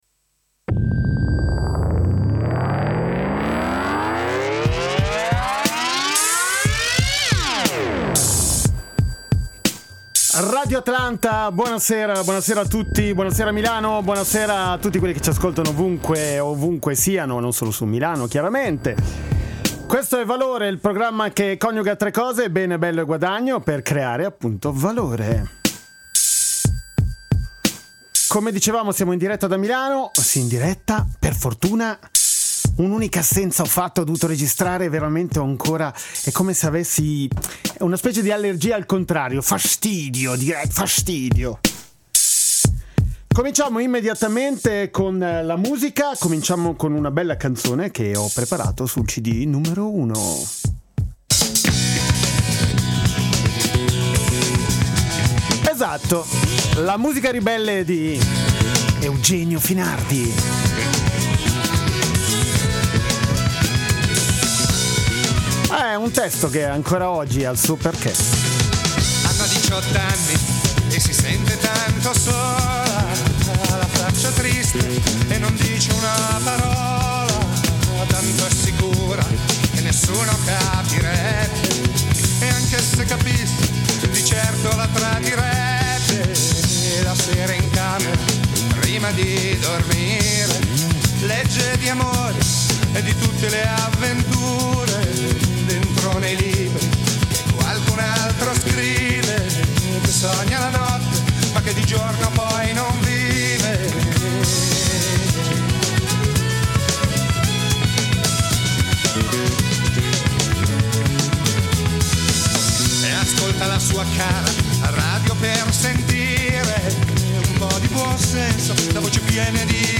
C’è una teoria, che si chiama appunto “la teoria del valore”, che dice che per creare Valore servono tre cose: bene, bello e guadagno. Musica e parole al servizio di pensieri in libertà, si passa dal rock al cantautorato.